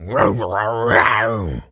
tas_devil_cartoon_14.wav